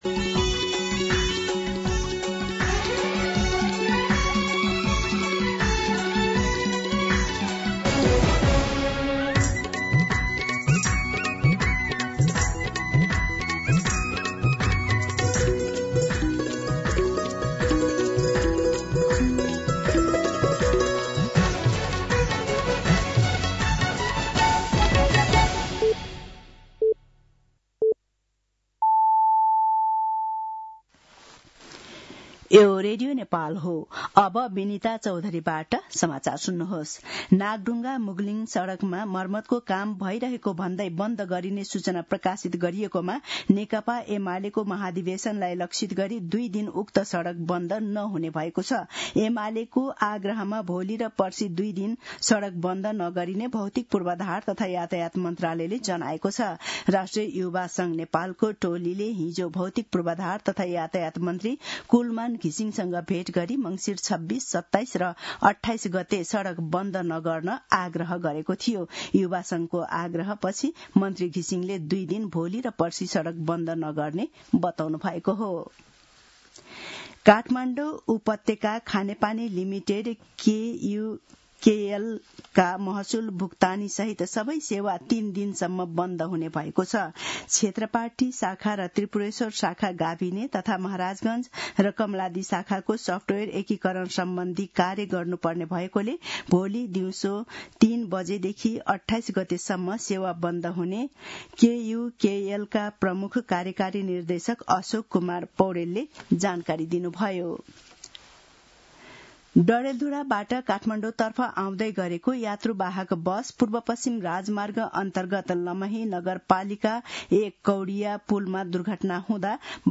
दिउँसो १ बजेको नेपाली समाचार : २५ मंसिर , २०८२